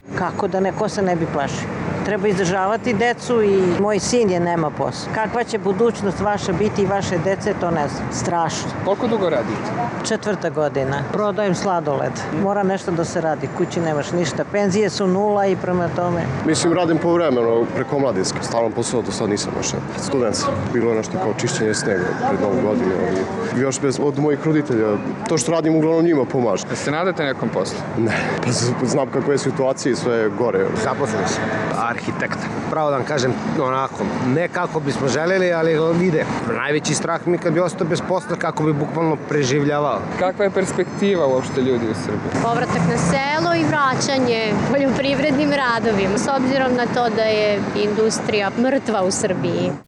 Beograđani za RSE kažu da su zbog rastuće besposlice sve više zabrinuti za budućnost: